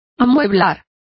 Complete with pronunciation of the translation of furnishing.